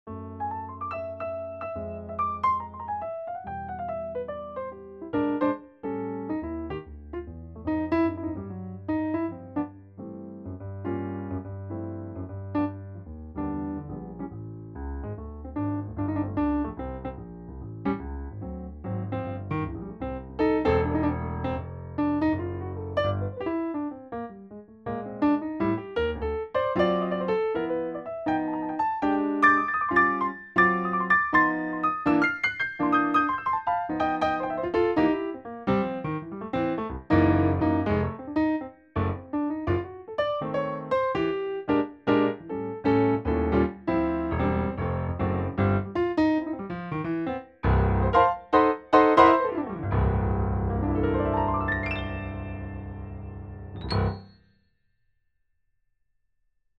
SK5 Jazz